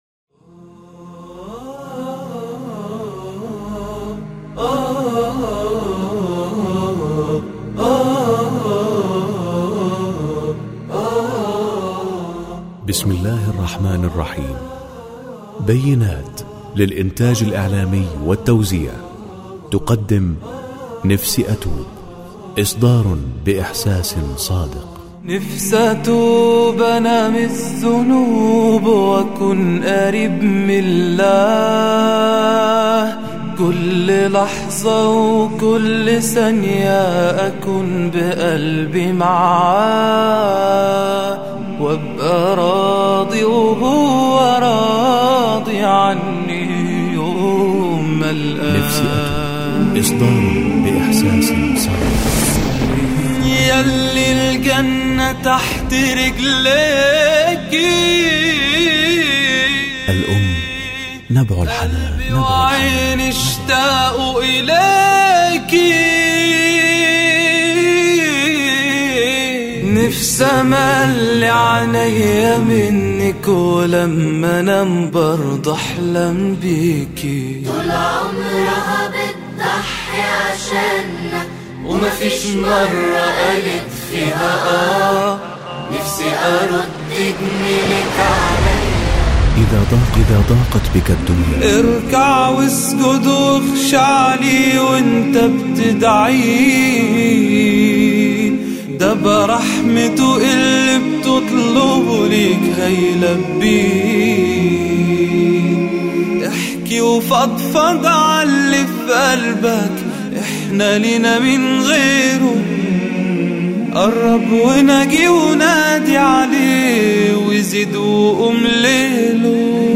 ألبوم رائع يجوز لي هذا النوع من الآداء المصري
صوت رائع
وهذا المنشد يتمتع بأداء وإحساس عالي وحنجرة ثمينة ..